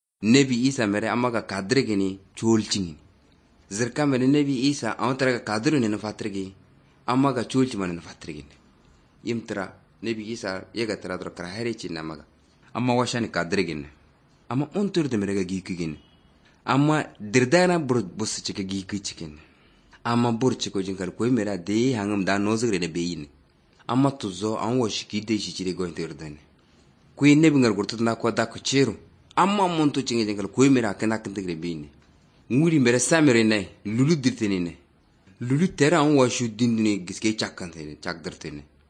The rhythm and phonology are vaguely reminiscent of Hausa and Fulani, but since I don’t recognise anything specific to either, I’m going to make a leap in the dark at another language in the Nigerian north and guess it might be a Nilo-Saharan language, perhaps Kanuri or Kanembu.
Plus, I can’t seem to detect any retroflex consonants.
The vowels sound more like those from Turkic, Uralic and Altaic families.